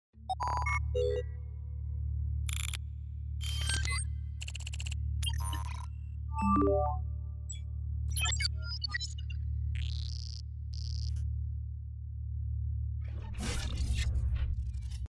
На этой странице собраны звуки голограмм — загадочные и футуристические аудиоэффекты, напоминающие технологии из научной фантастики.